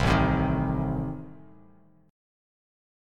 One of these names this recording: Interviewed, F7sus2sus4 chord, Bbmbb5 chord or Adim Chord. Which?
Adim Chord